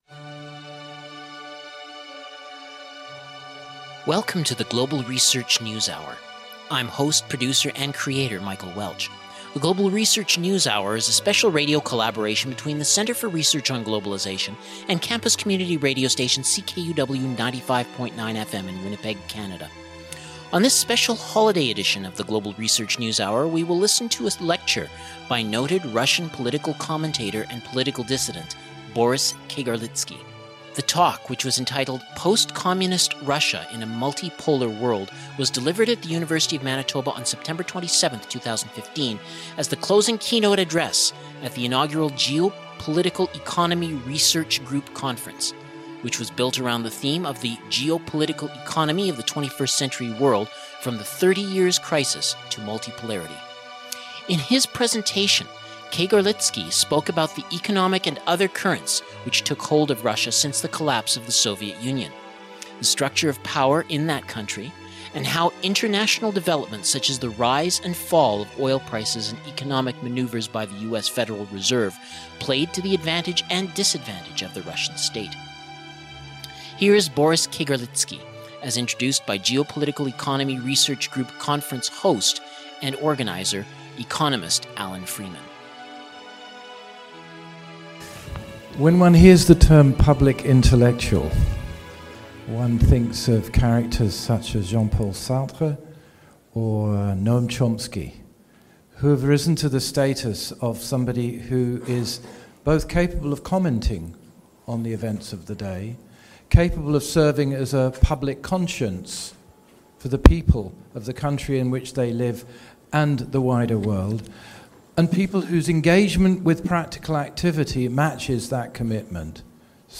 Lecture by Boris Kagarlitsky
Jan_1_GRNH_Kagarlitsky_talk_on_post_communist_Russia.mp3